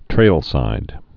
(trālsīd)